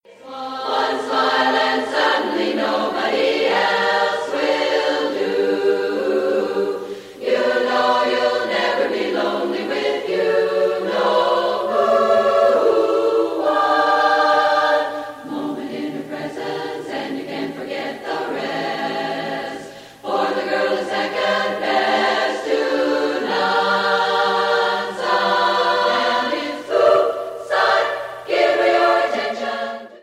arranged for chorus (more than 4 voice parts) includes